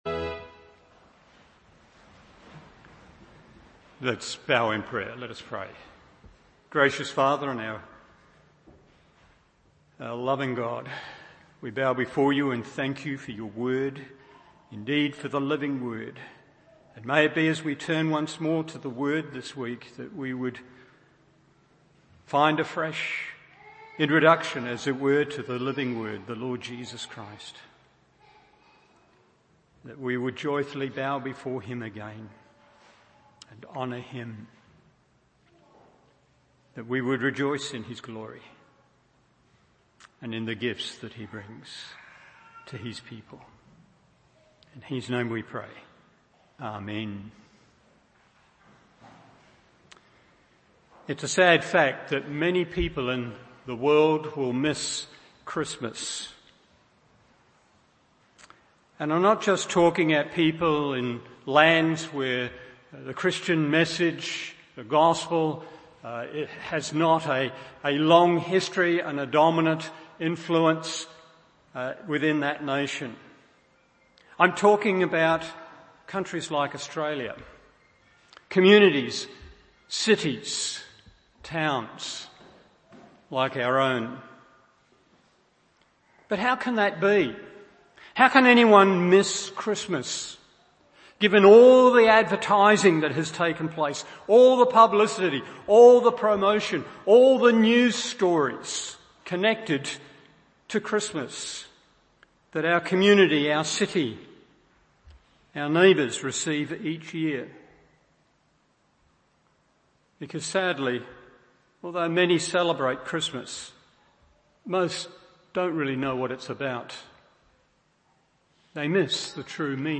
Christmas Service 2019